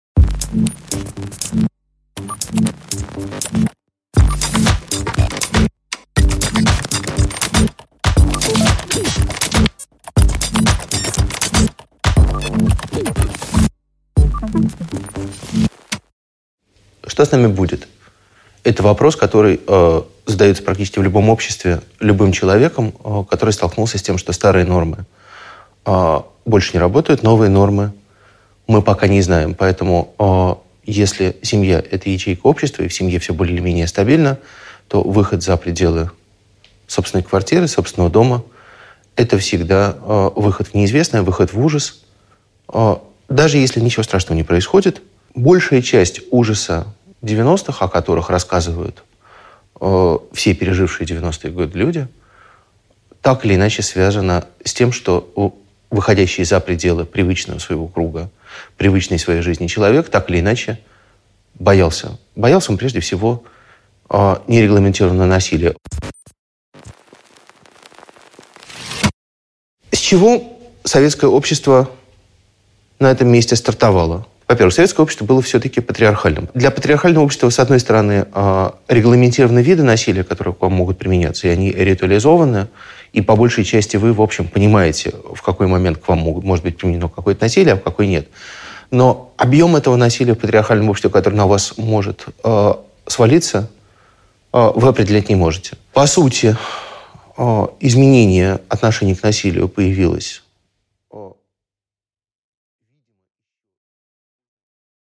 Аудиокнига Агрессия: образ постсоветского насилия | Библиотека аудиокниг
Прослушать и бесплатно скачать фрагмент аудиокниги